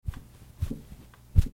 Download Bed sound effect for free.
Bed